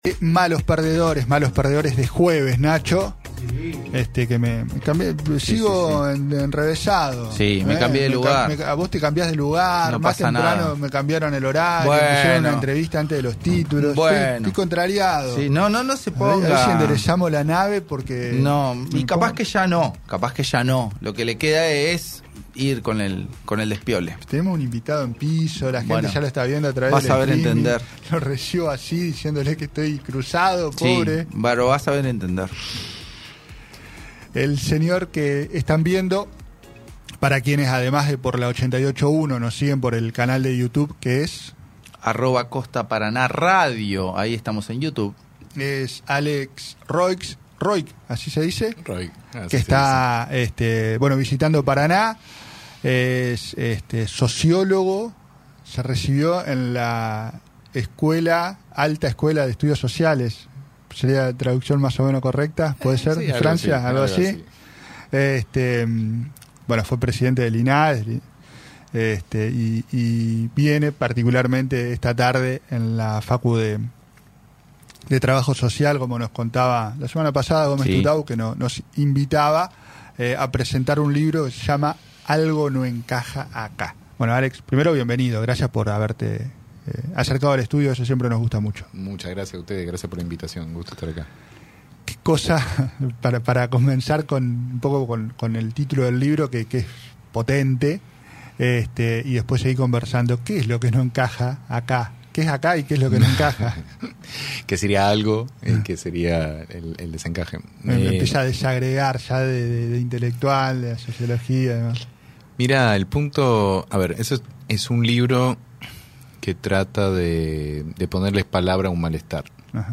Unas horas antes estuvo en los estudios de Radio Costa Paraná (88.1)